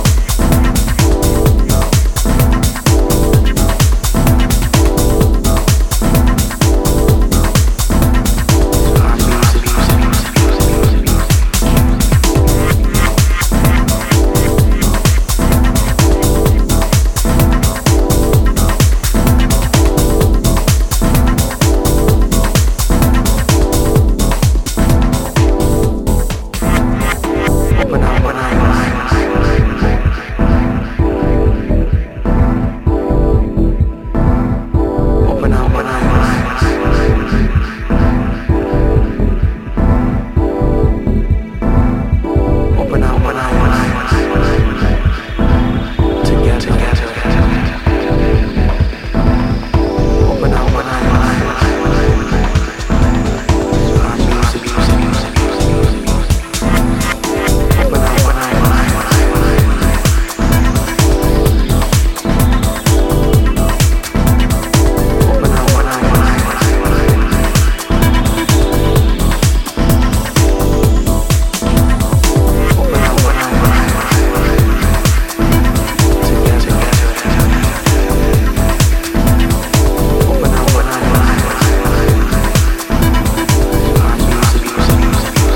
UK tech house pioneers